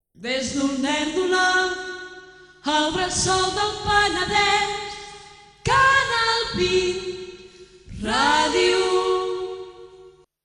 Identificació cantada de l'emissora